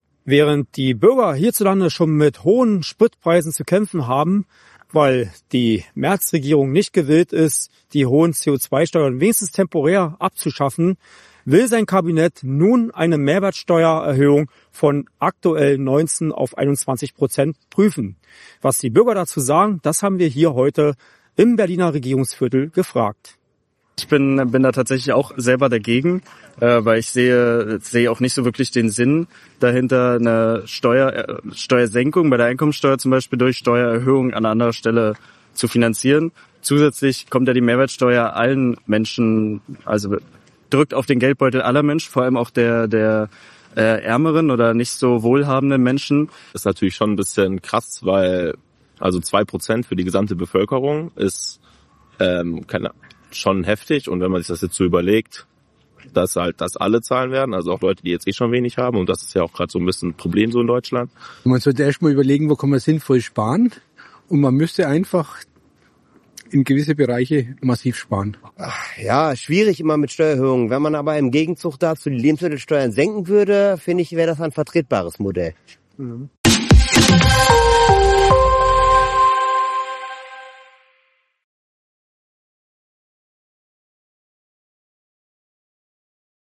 Aus Berlin berichtet